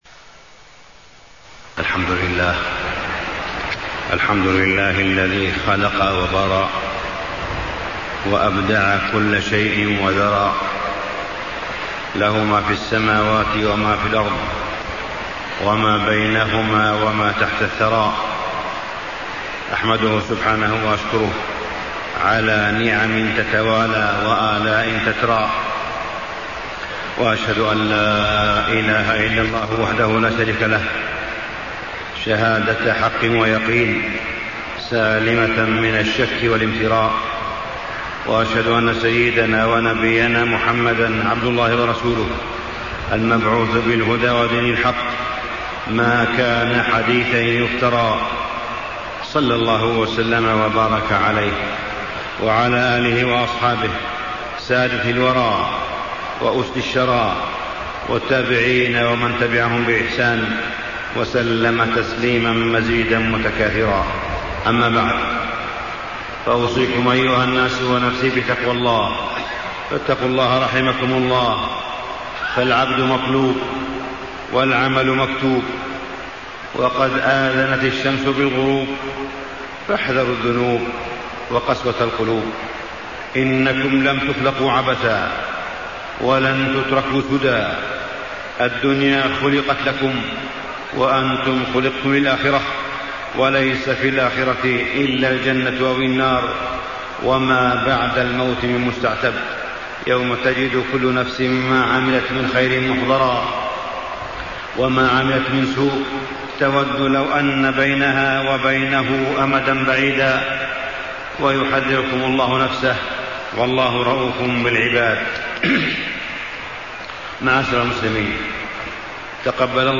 تاريخ النشر ٢٠ رمضان ١٤٣٥ هـ المكان: المسجد الحرام الشيخ: معالي الشيخ أ.د. صالح بن عبدالله بن حميد معالي الشيخ أ.د. صالح بن عبدالله بن حميد محبطات الأعمال